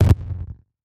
Звуки глитч-эффекта
На этой странице собраны различные звуки глитч-эффектов — от резких цифровых помех до плавных искажений.